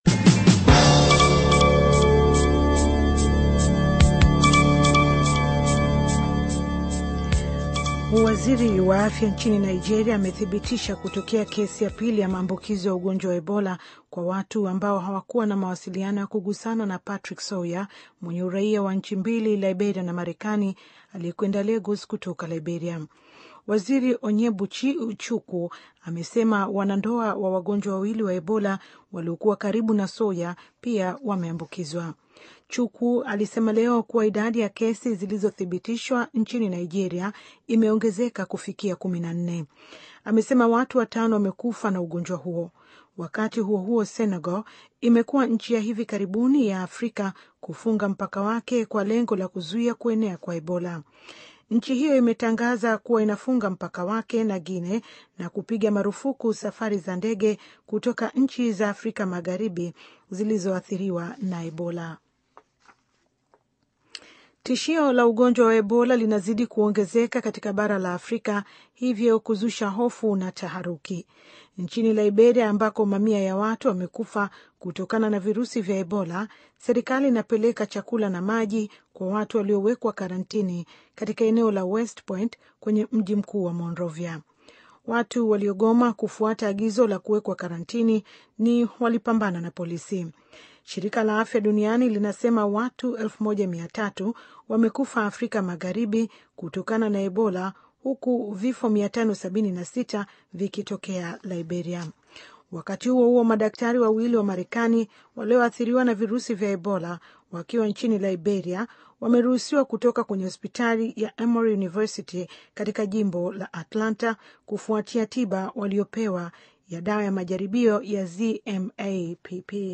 Taarifa ya habari - 6:24